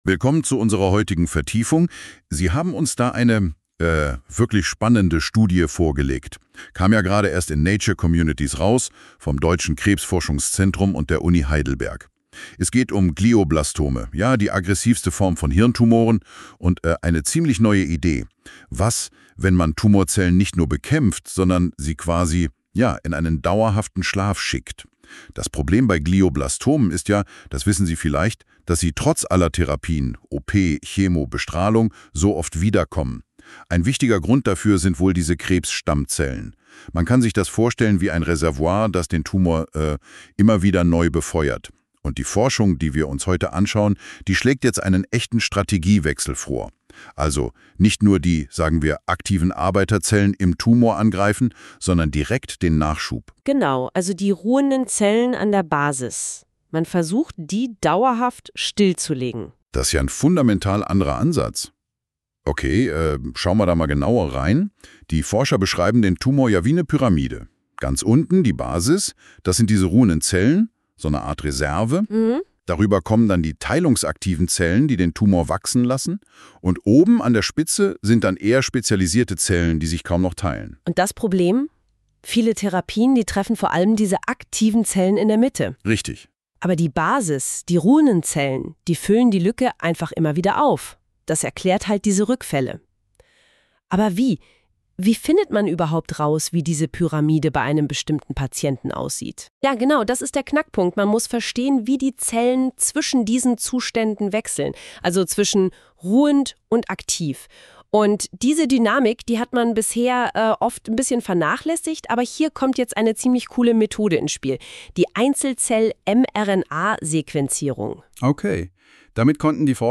Die Pressemitteilung als Mini-Podcast:
(KI-generiert)